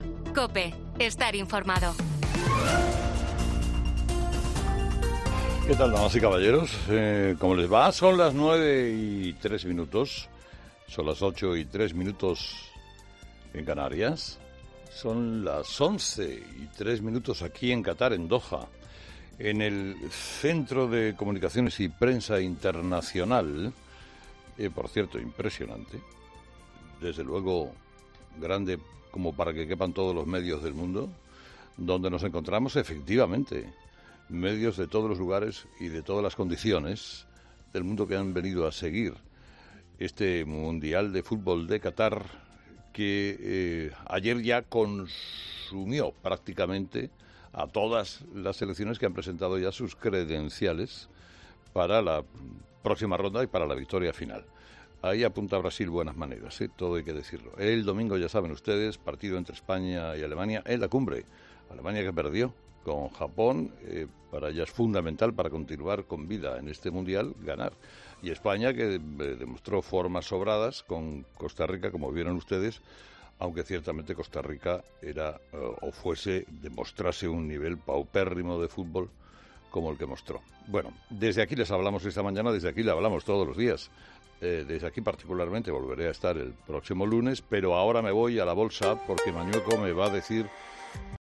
Desde el set de COPE en Qatar